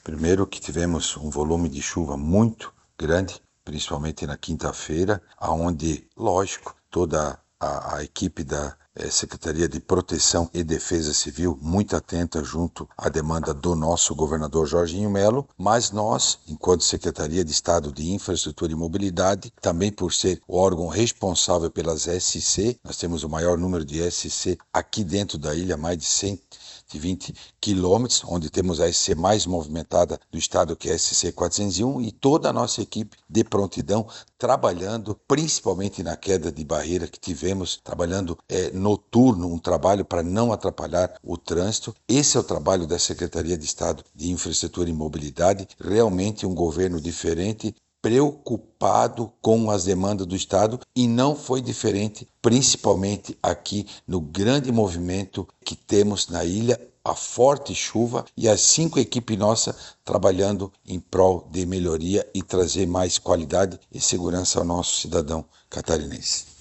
SECOM-Sonora-Secretario-Infraestrutura-Recuperacao-SCs-Chuvas.mp3